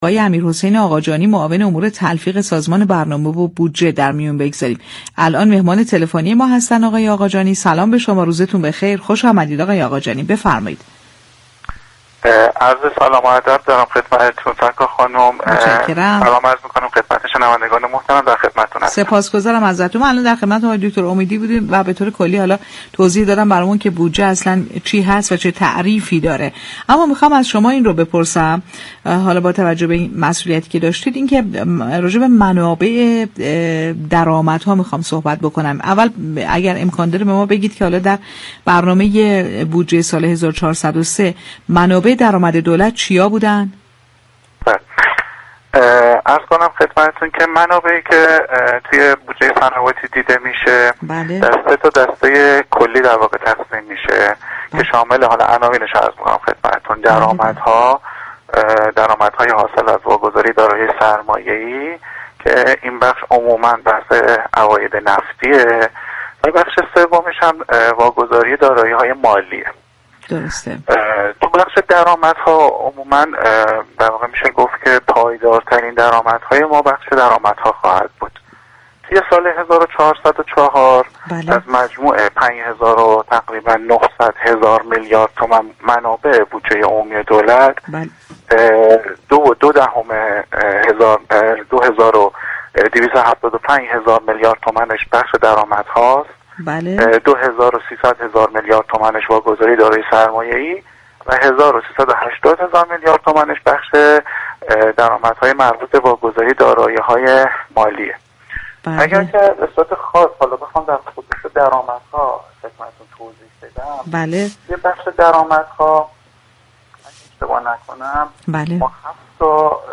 به گزارش پایگاه اطلاع رسانی رادیو تهران، امیرحسین آقاجانی معاون امور تلفیق سازمان برنامه و بودجه كشور در گفت و گو با «بازار تهران» اظهار داشت: منابع بودجه عمومی دولت از سه قسمت درآمدها، واگذاری دارایی‌های سرمایه‌ای و واگذاری دارایی‌های مالی تشكیل شده است.